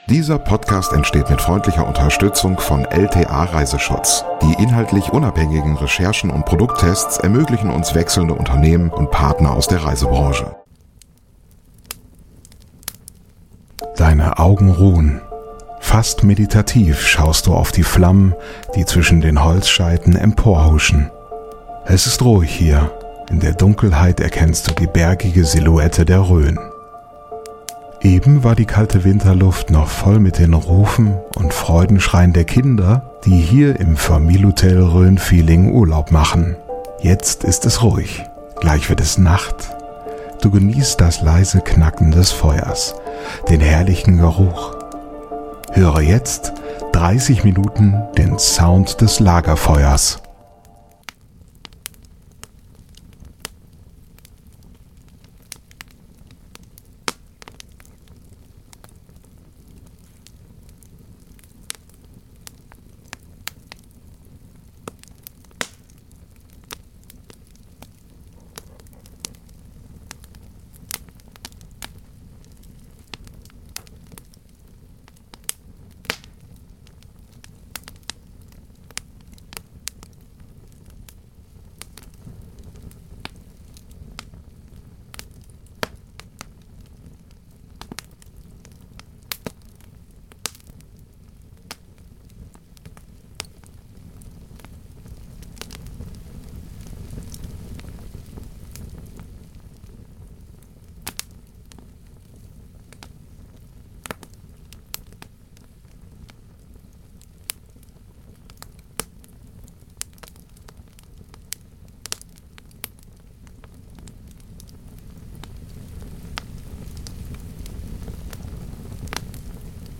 ASMR Lagerfeuer: Ambient 3D-Sound zum Einschlafen ~ Lieblingsreisen - Mikroabenteuer und die weite Welt Podcast
Es ist ruhig hier, in der Dunkelheit erkennst du die bergige Silhoutte der Rhön.
Du genießt das leise Knacken des Feuers, den herrlichen Geruch. Höre jetzt 30 Minuten den Sound des Lagerfeuers.